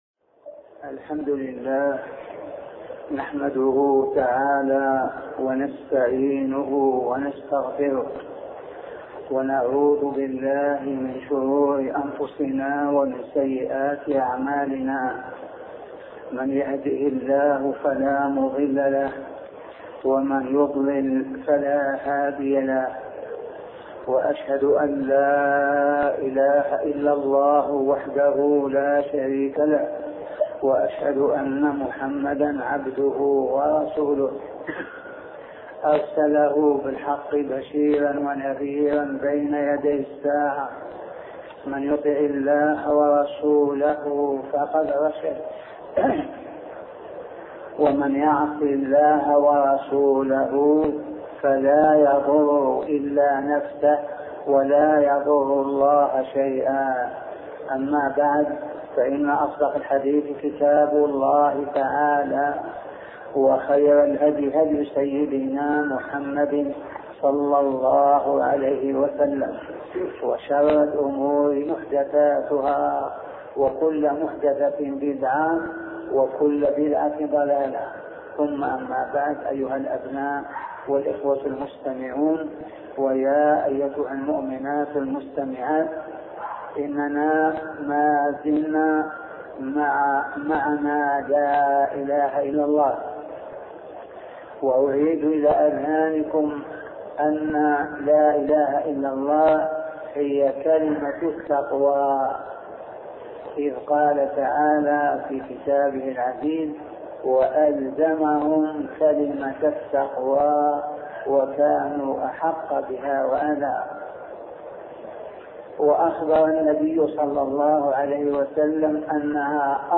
سلسلة محاطرات بعنوان معنى لا إله إلا الله